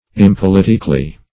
impoliticly - definition of impoliticly - synonyms, pronunciation, spelling from Free Dictionary Search Result for " impoliticly" : The Collaborative International Dictionary of English v.0.48: Impoliticly \Im*pol"i*tic*ly\, adv. In an impolitic manner.